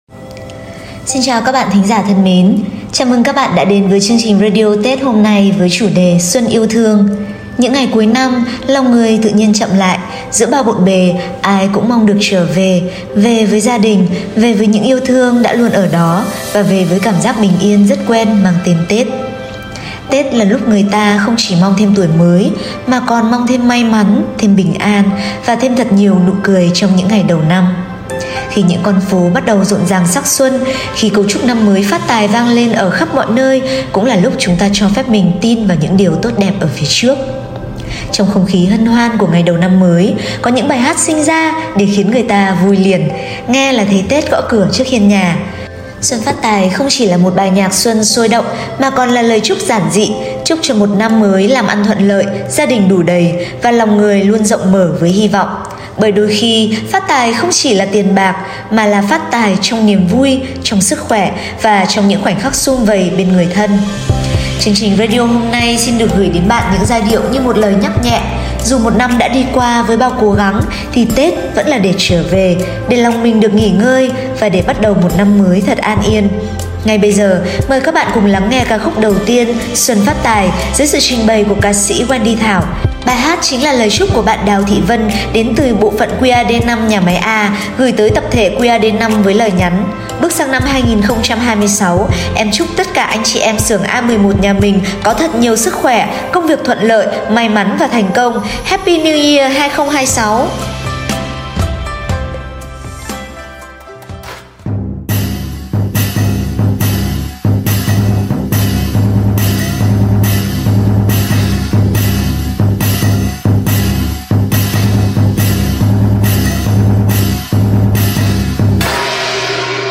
Regina Miracle Radio số 19 mang chủ đề “Xuân yêu thương” – là món quà âm nhạc gửi đến toàn thể anh chị em Regina trong những ngày đầu năm mới 2026. Từ giai điệu rộn ràng của Xuân phát tài, những bản tình ca nhiều cảm xúc, cho đến lời chúc Một năm mới bình an và Vạn sự như ý — mỗi bài hát là một lời nhắn gửi chân thành, một chút ấm áp giữa mùa xuân đoàn viên.